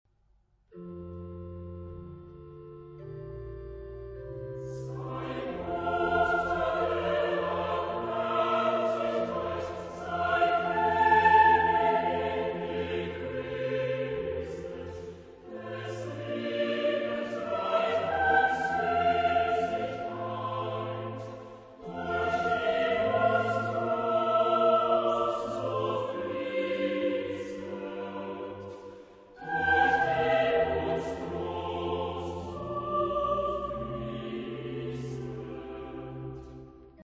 Genre-Style-Form: Sacred ; Choir ; Motet
Mood of the piece: homophonic ; contemplative ; andante
Type of Choir: SATB  (4 mixed voices )
Instrumentation: Organ  (1 instrumental part(s))
Tonality: F major